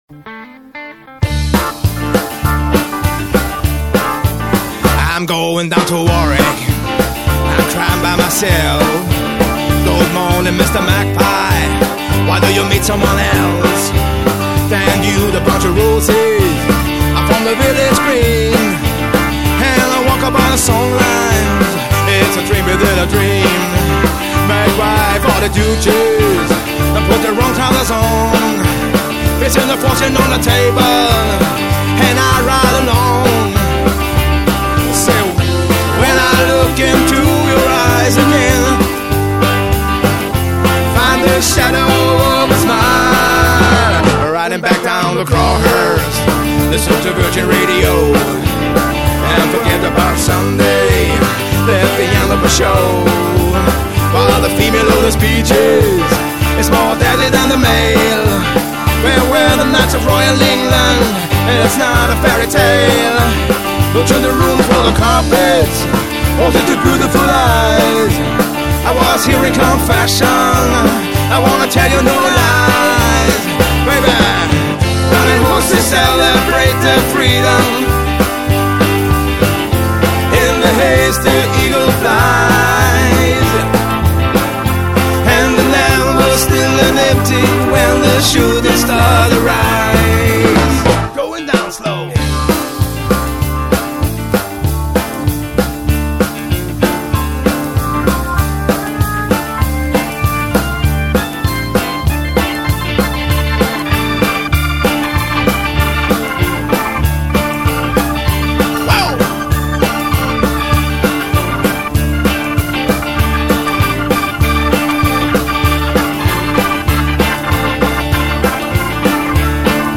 keyboards & violine